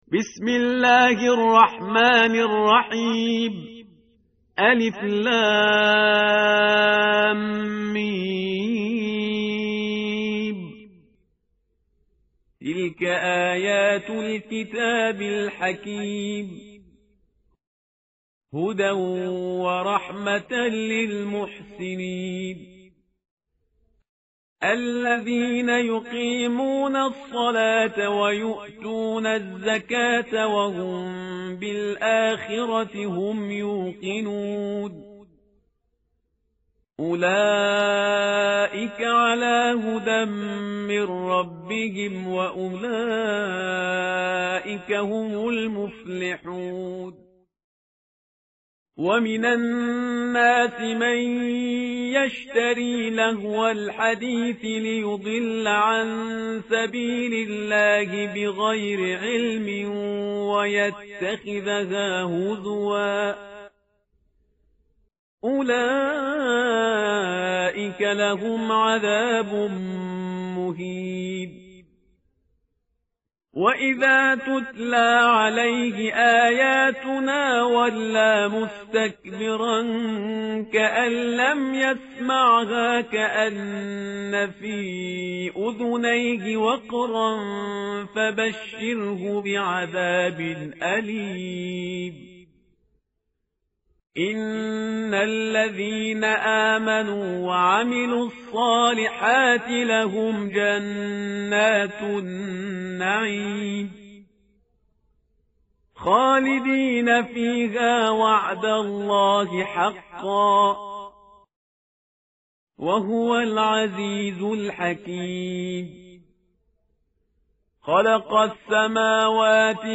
متن قرآن همراه باتلاوت قرآن و ترجمه
tartil_parhizgar_page_411.mp3